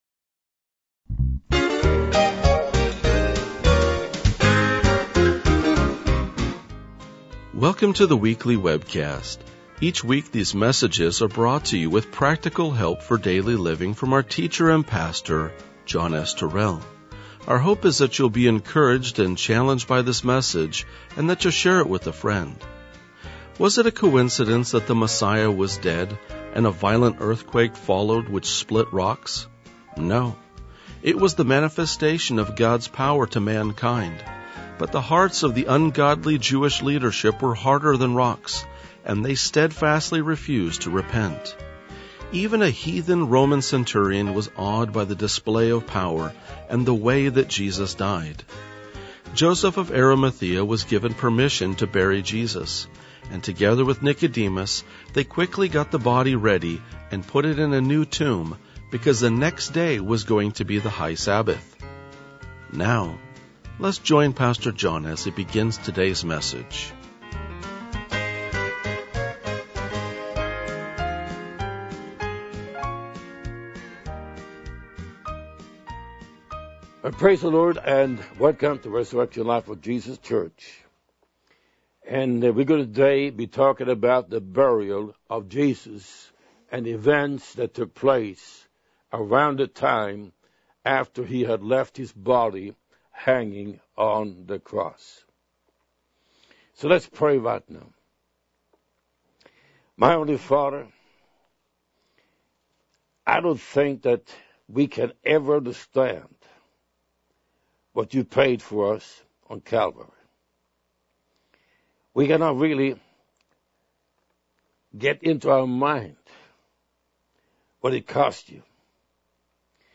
RLJ-1988-Sermon.mp3